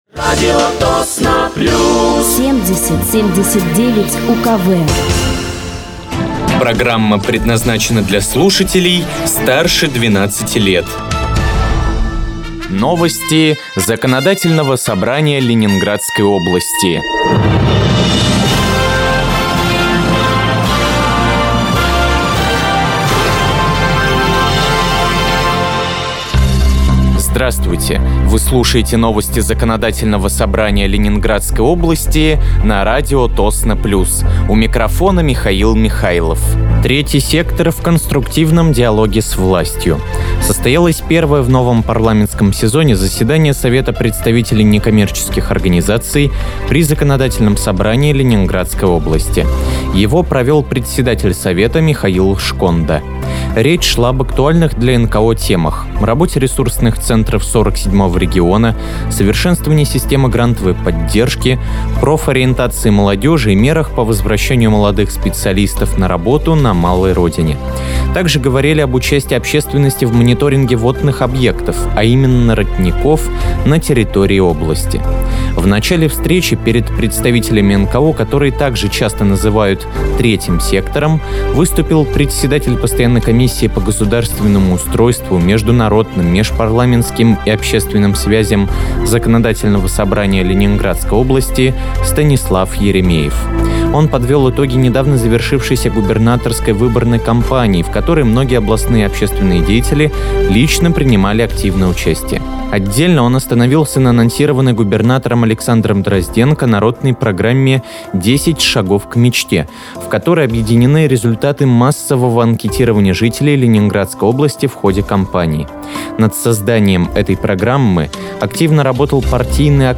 Выпуск новостей Законодательного собрания Ленинградской области от 23.09.2025
Вы слушаете новости Законодательного собрания Ленинградской области на радиоканале «Радио Тосно плюс».